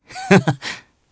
Theo-Vox_Happy2_jp.wav